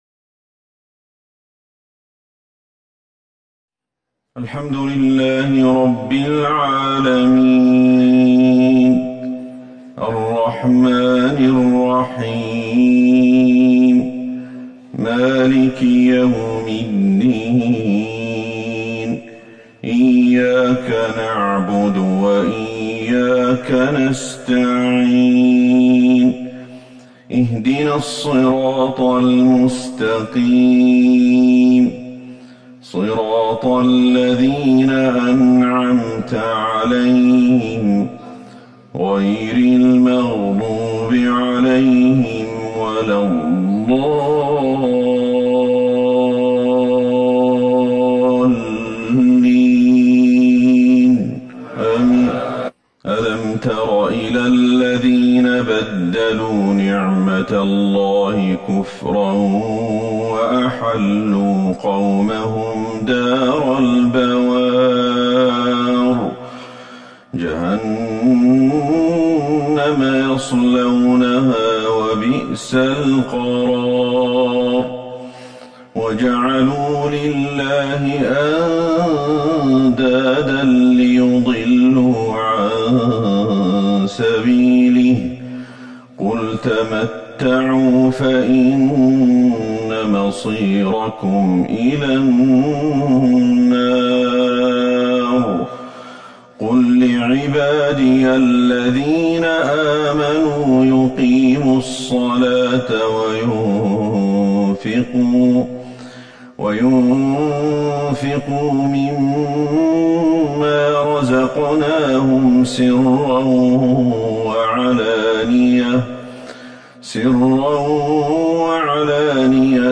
صلاة الفجر 5 جمادى الاولى 1441 من سورة ابراهيم